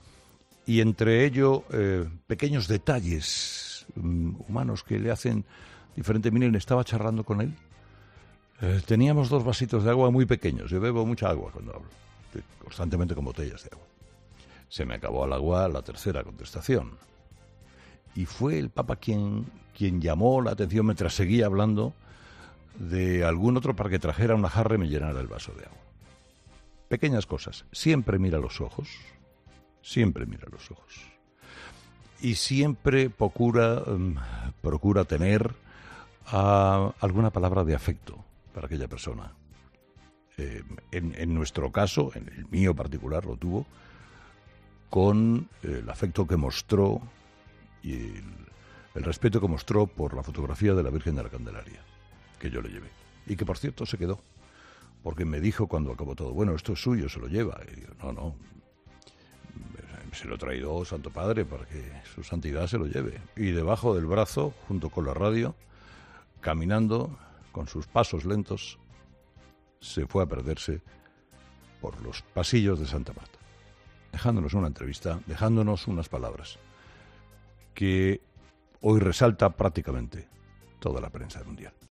Un día después de la histórica entrevista realizada por Carlos Herrera al Papa Francisco, el comunicador ha desvelado en antena algunos detalles "humanos" y "situaciones particulares" de la mediática charla que tuvo lugar en la biblioteca de la residencia de Santa Marta, donde vive el Pontífice.